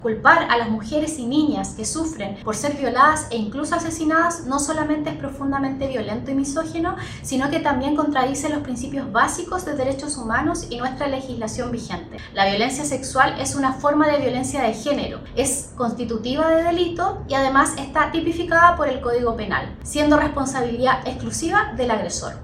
Asimismo, la seremi de la Mujer, Camila Contreras, señaló que esto atenta contra los derechos humanos y está vulnerando la ley, algo que no pueden permitir, ya que él está fomentando el odio contra las mujeres, justificando los delitos sexuales.